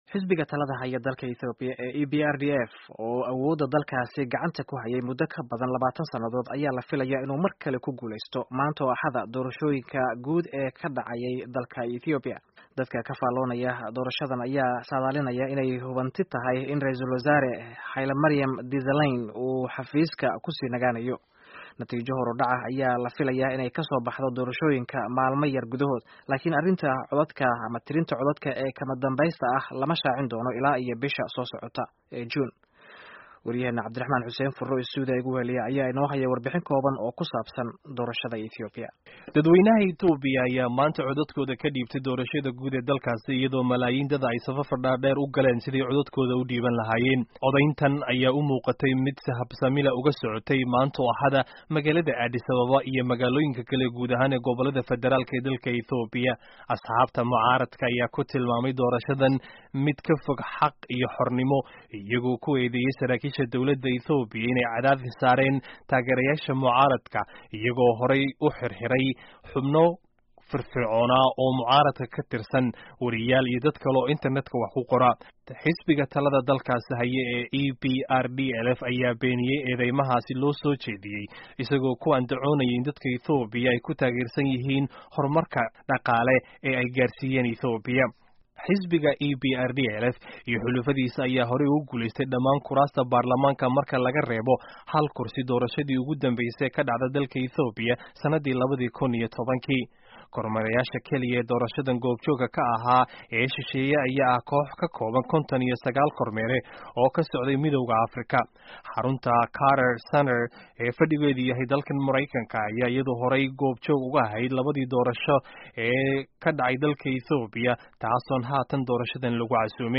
Warbixinta doorashada Itoobiya